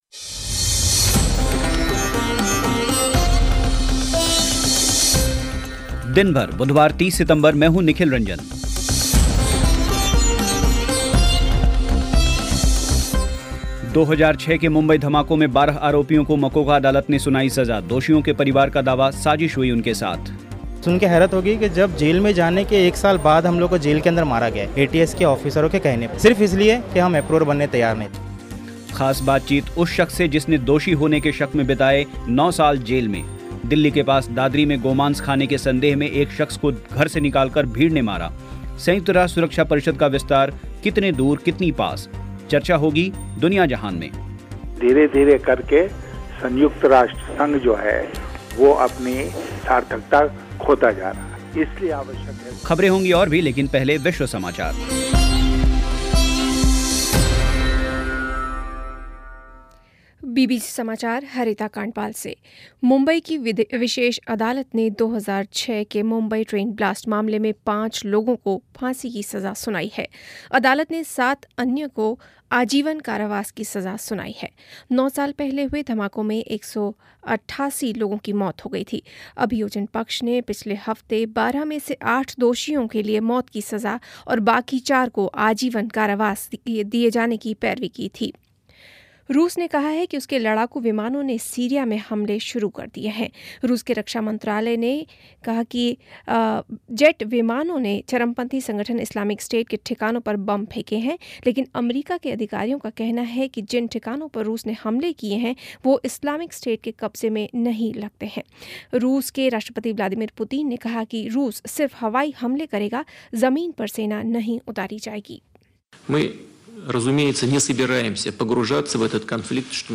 2006 के मुंबई धमाकों में 12 आरोपियों को मकोका अदालत ने सुनाई सज़ा, दोषियों के परिवार का दावा साजिश हुई उनके साथ ख़ास बातचीत उस शख्स जिसने दोषी होने के शक में बिताए 9 साल जेल में दिल्ली के पास दादरी में गोमांस खाने के संदेह में एक शख्स को घर से निकाल कर भीड़ ने मारा संयुक्त राष्ट्र सुरक्षा परिषद का विस्तार कितना दूर कितने पास चर्चा दुनिया जहान में